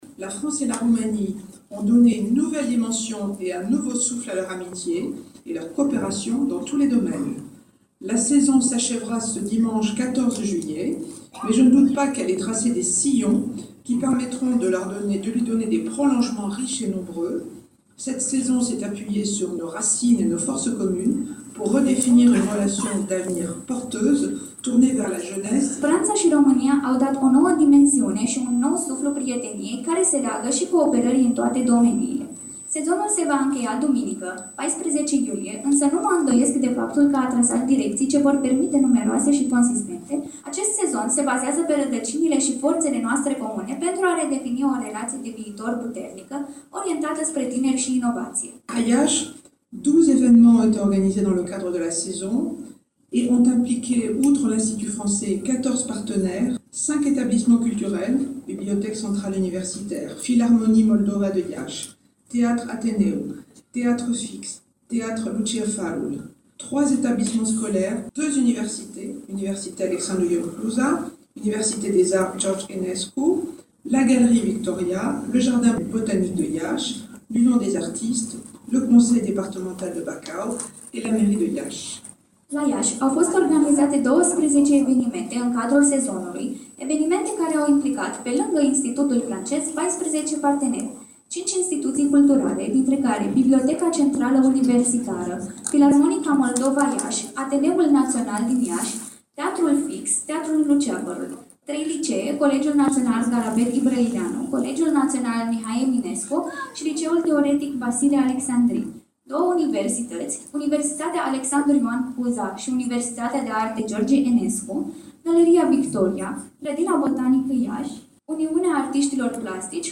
Sărbătoarea Națională a Franței a debutat deja la Iași, cu Participarea Excelenței Sale, doamna Michèle Ramis, Ambasadorul Franței în România – pe care o ascultăm, în traducere, și aici.
Prez-sonora-Ambasadoarea-F.mp3